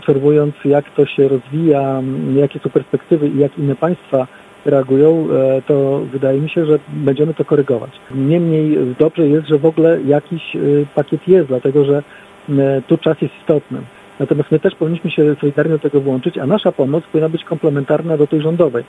– Najbardziej poszkodowani mogą liczyć na pomoc miasta – mówi Wojciech Karol Iwaszkiewicz, burmistrz Giżycka.